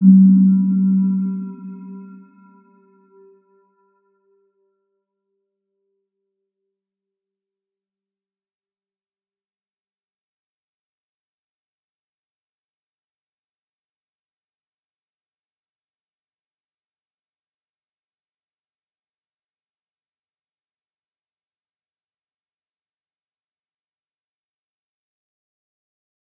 Round-Bell-G3-mf.wav